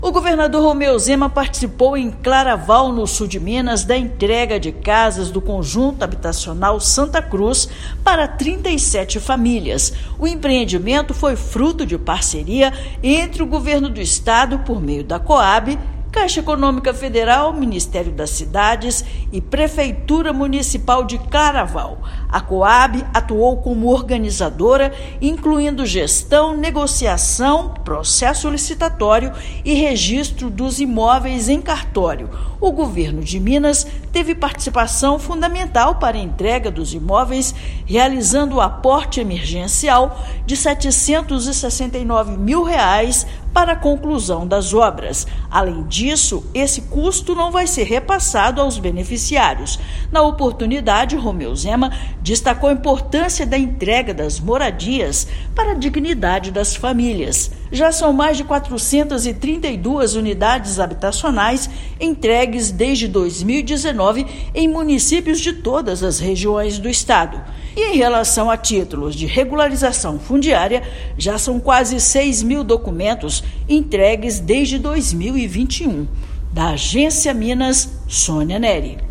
Participação do Estado foi fundamental para a entrega do empreendimento, com aporte emergencial que permitiu a conclusão das obras de construção das casas. Ouça matéria de rádio.